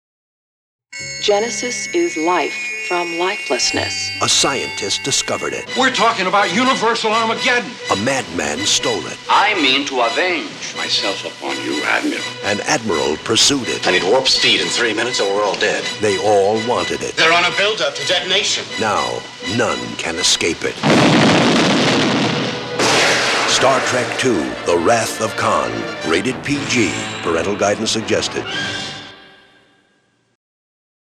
Radio Spots
The spots are very good and capture the action and mood of the movie.